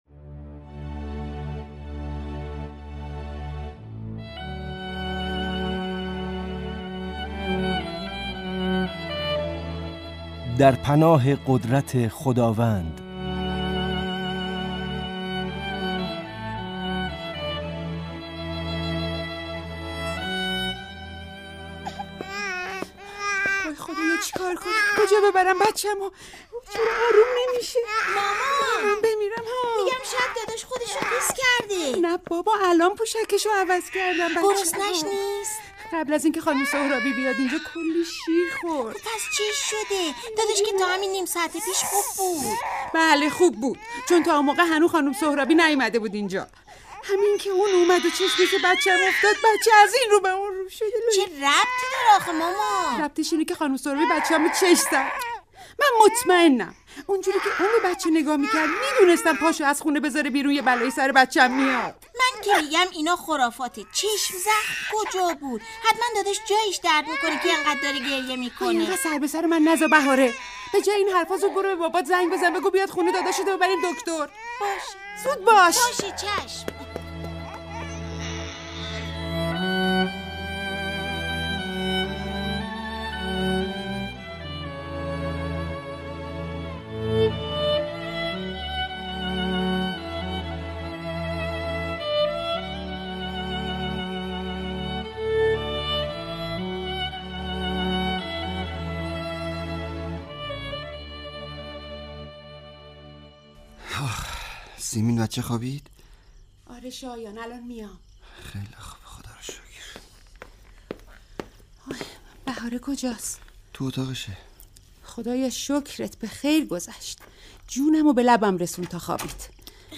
به گزارش خبرنگار مهر، نمایش رادیویی «در پناه قدرت خداوند» کاری از هنرمندان اداره کل هنرهای نمایشی رادیو است که به داستان مادری می پردازد که فرزند نوزادش دایم گریه می کند و او نمی داند این گریه ها از چیست اما آن را به چشم زخم همسایه مرتبط می داند.